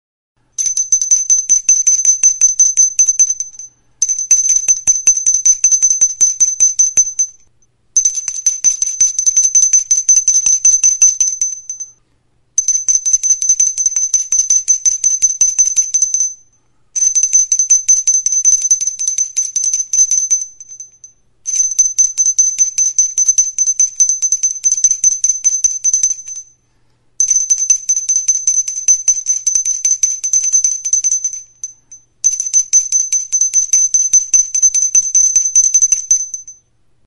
Idiófonos -> Golpeados -> Indirectamente
Grabado con este instrumento.
Keramikazko ezkilatxoa da. Mihia ere buztinezkoa da.